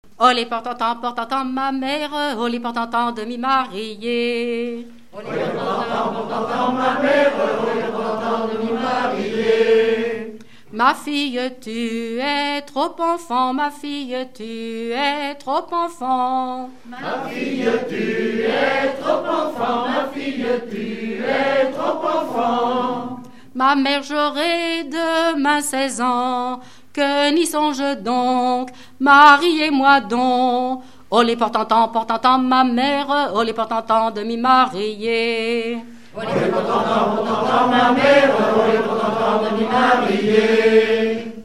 Dialogue mère-fille
Regroupement de chanteurs du canton
Pièce musicale inédite